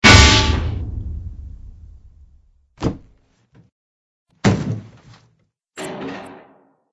AA_drop_safe.ogg